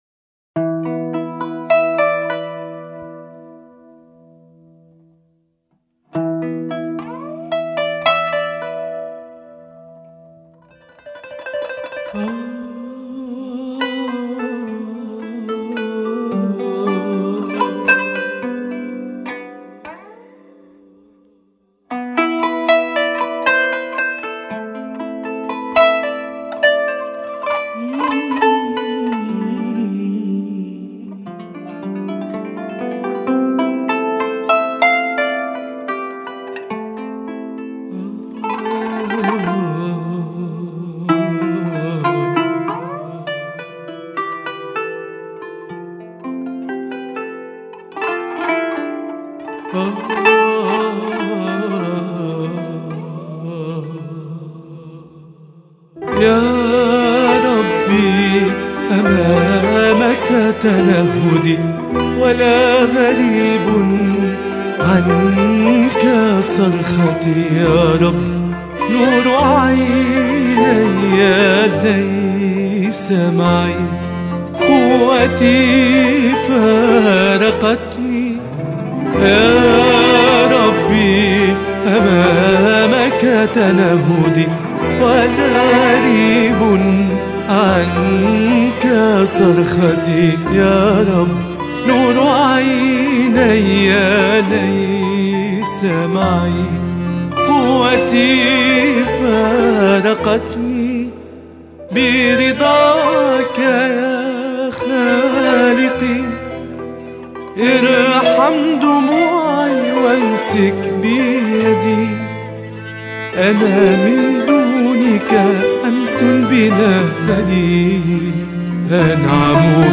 ترتيلة